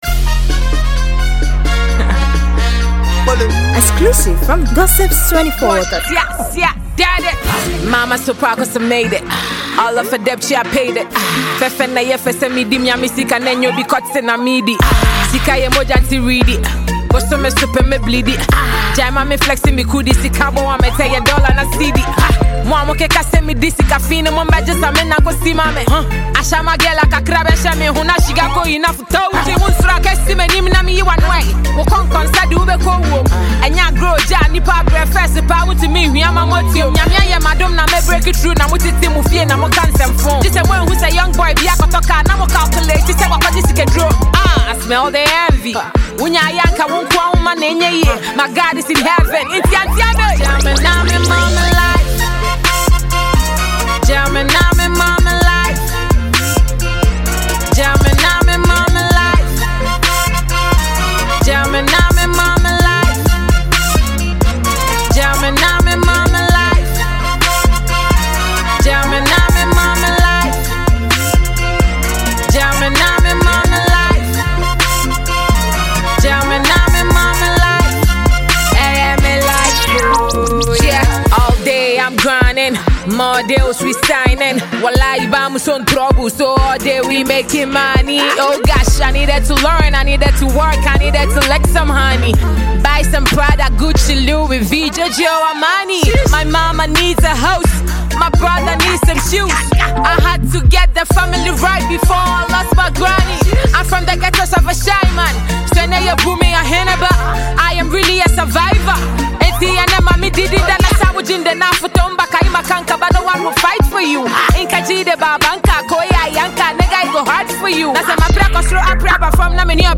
Ghanaian Rap heavyweight
Hip-Hop jam